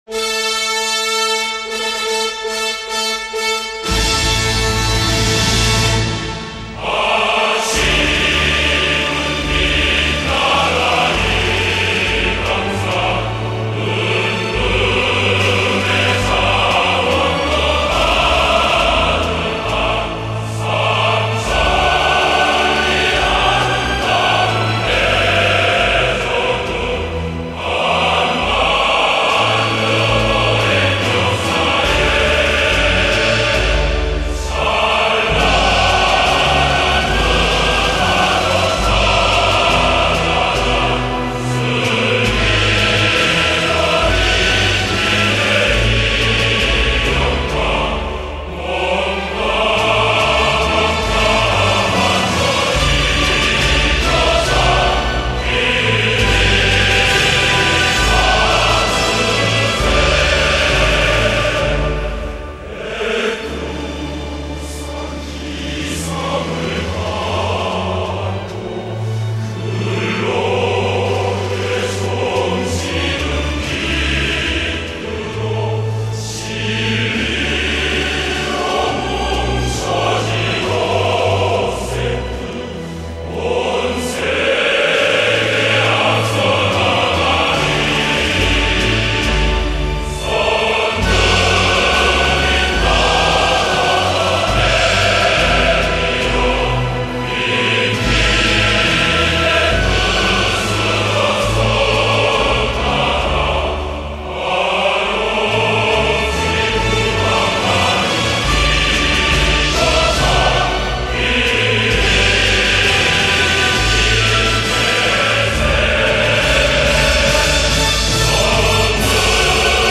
相对之下 朝鲜的 就很庄重了